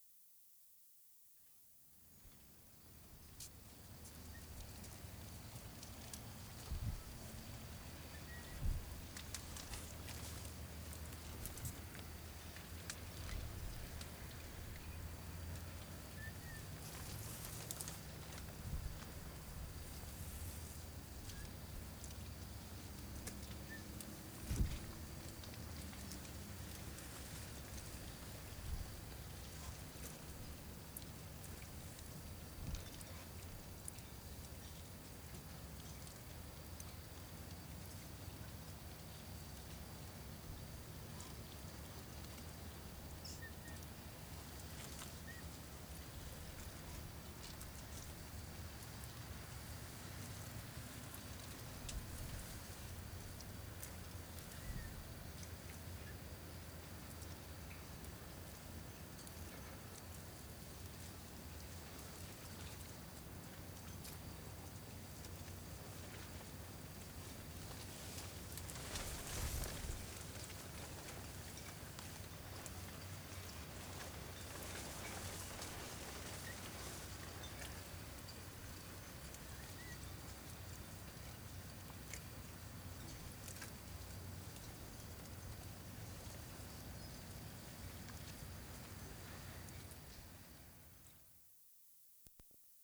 WORLD SOUNDSCAPE PROJECT TAPE LIBRARY
5. BURRARD MARINA, yacht mooring 1'10"
7. A fluttering piece of plastic over a boat, moving slightly in the wind. Not that clear. Lots of traffic and still the tinkling in background.